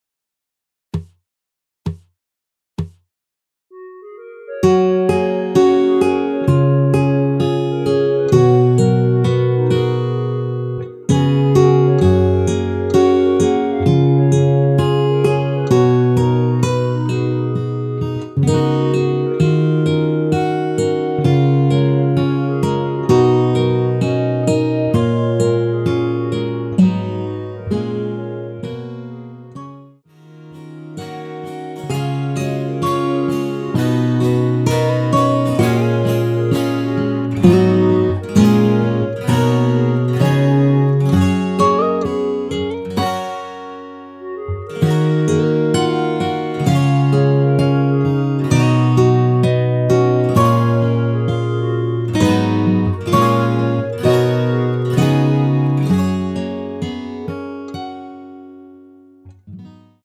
전주없이 시작 하는 곡이라 카운트 넣어 놓았습니다.(미리듣기 참조)
원키에서(+3)올린 (1절+후렴)으로 진행되는 멜로디 포함된 MR입니다.
F#
앞부분30초, 뒷부분30초씩 편집해서 올려 드리고 있습니다.
중간에 음이 끈어지고 다시 나오는 이유는